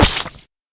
Added sound for when pumpkin hits you or lands near by.
pumpkin_splat.ogg